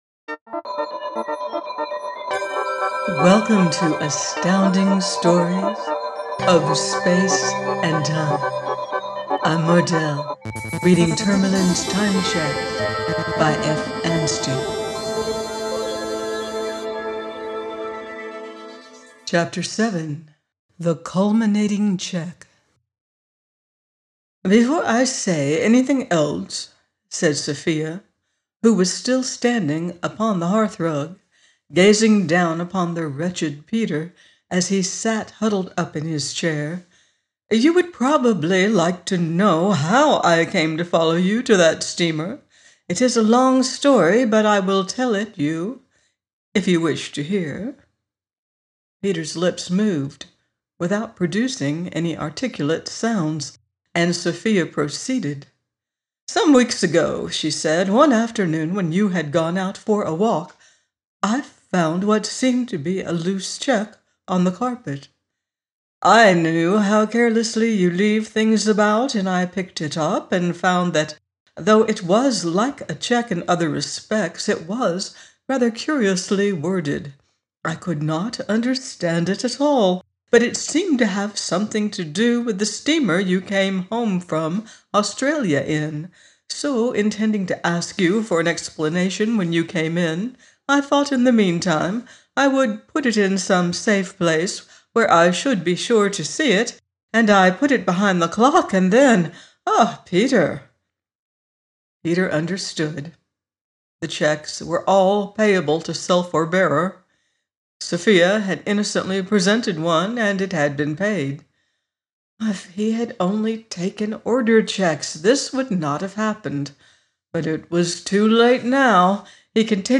Tourmalin’s Time Cheques – by F. Anstey - audiobook